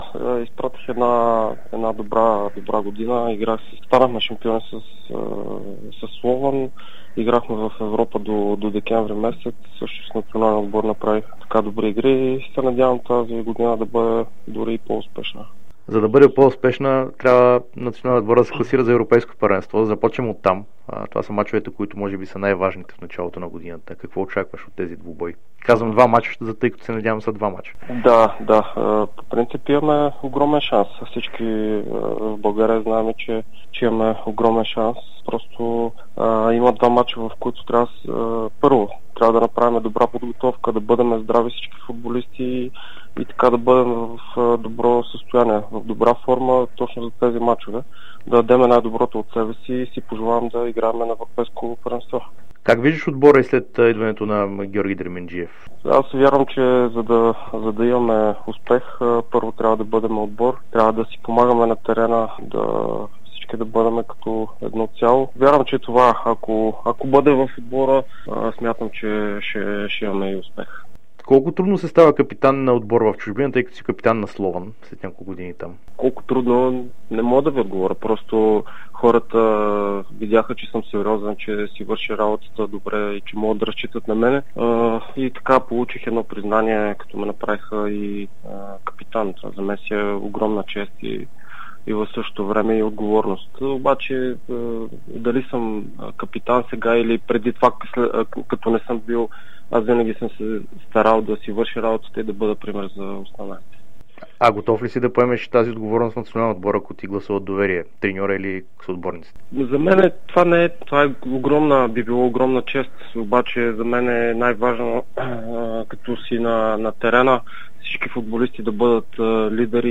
Българският национал Васил Божиков, който стана шампион на Словакия и игра в групите на Лига Европа с екипа на Слован, даде специално интервю за dsport. Защитникът сподели, че е изпратил успешна година, но се надява следващата да е още по-добра.